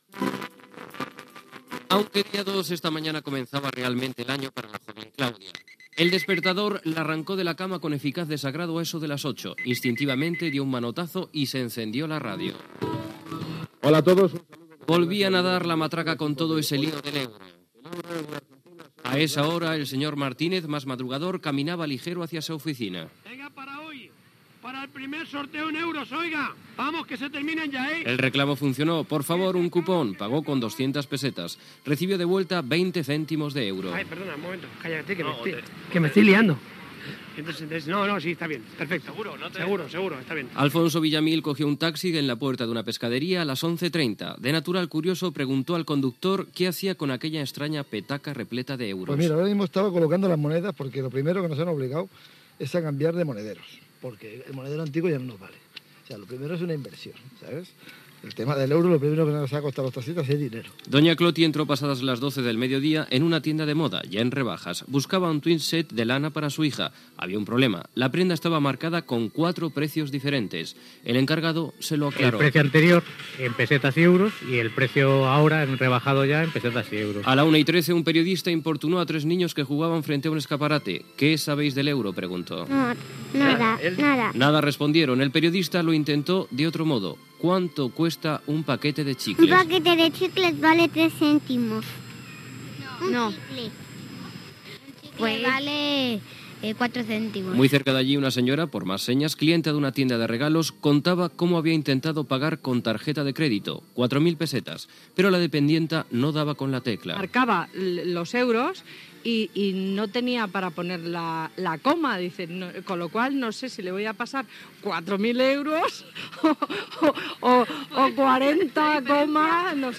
Reportatge de casos diversos en el primer dia feiner que es fan servir els euros
Informatiu
Qualitat d'àudio defectuosa als primers segons.